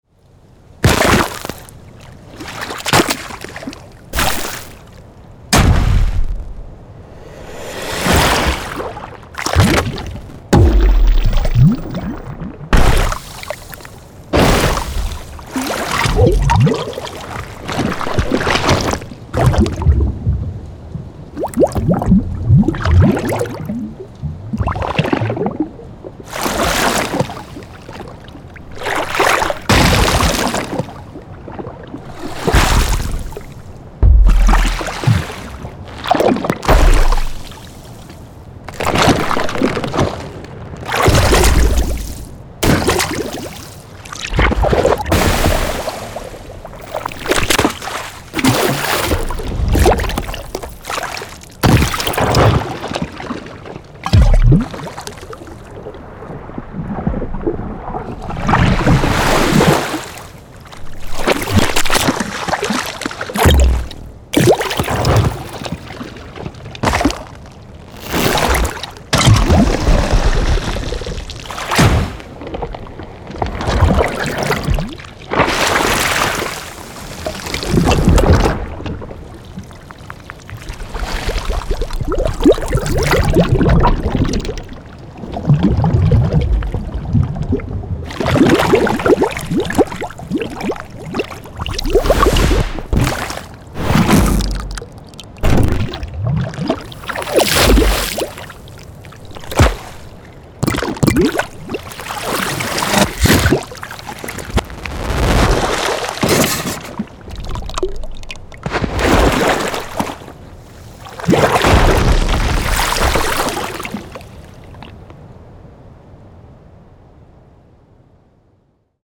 Sound Effects Packs